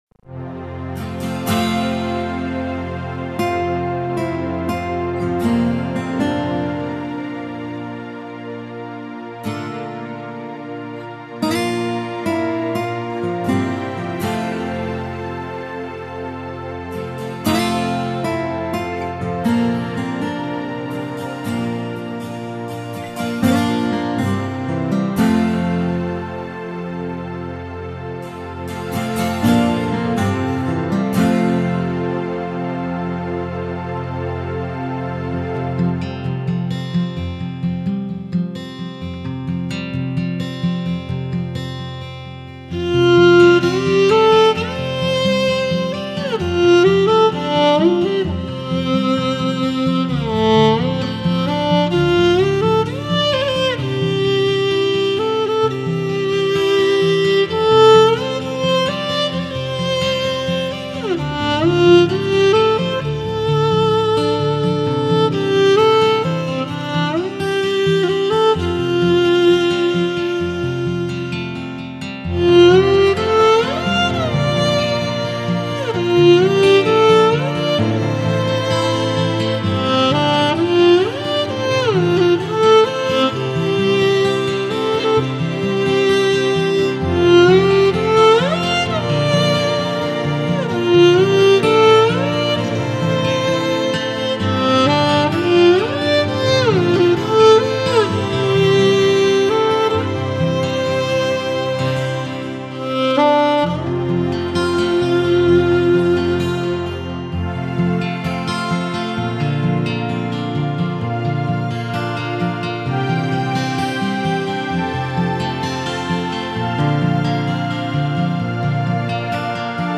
弦音袅袅 琴声悠扬 在跌宕起伏的旋律里诉说衷肠
马头琴是蒙古民族最具有代表性的乐器，其音色纯朴、浑厚、极富感染力，这也是只有在草原上才能听到的声音。